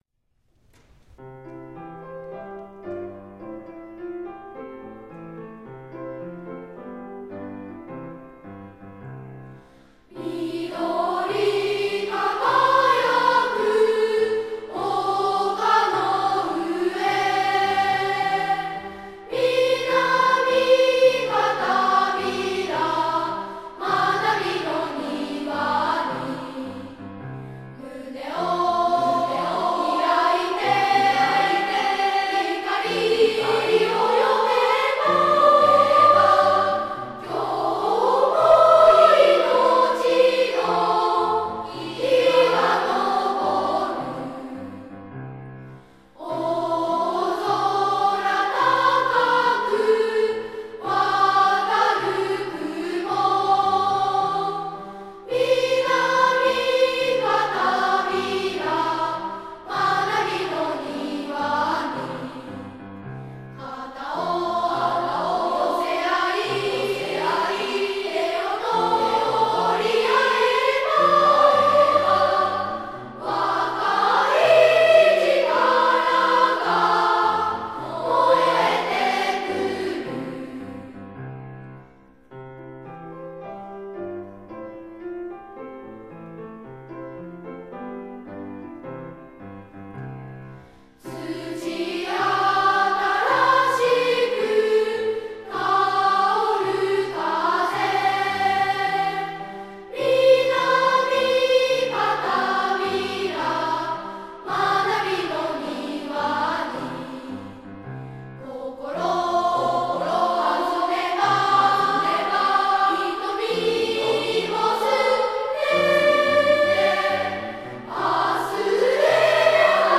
校歌
永縄　半助　作詞　／　和田　三里　作曲
01-校歌（歌あり）.m4a